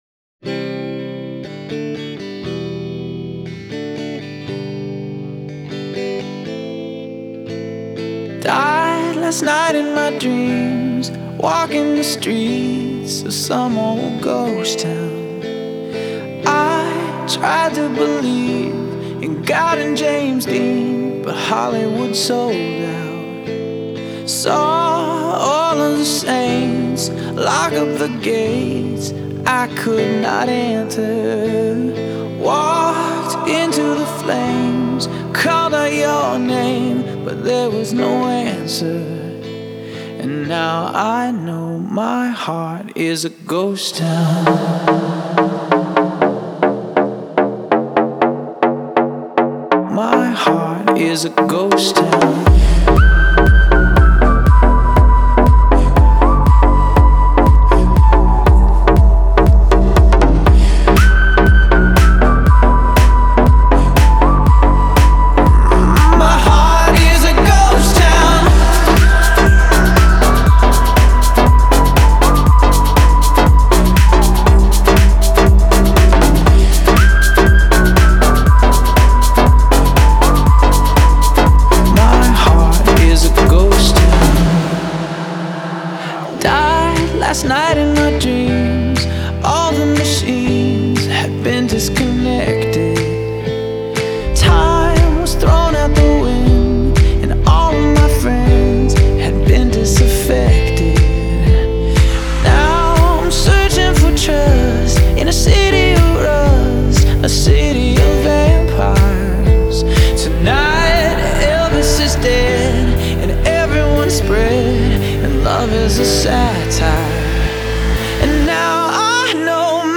Genre: Pop, Dance